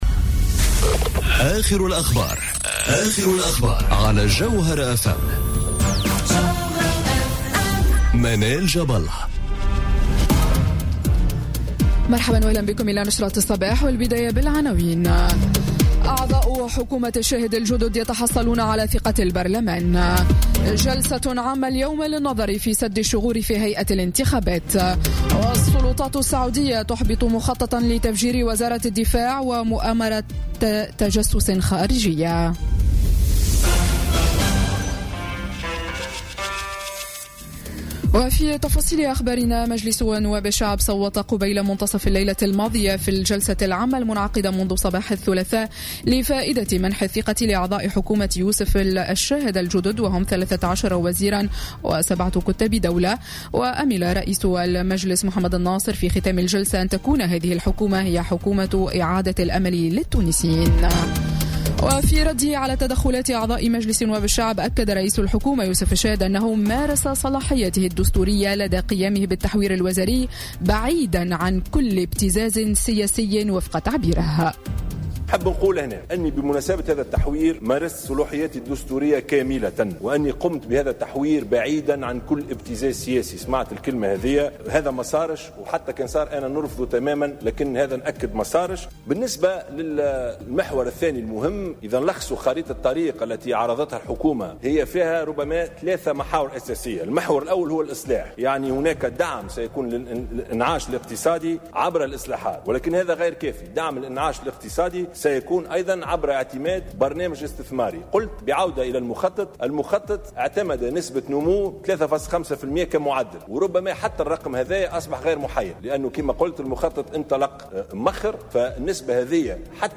نشرة أخبار السابعة صباحا ليوم الثلاثاء 12 سبتمبر 2017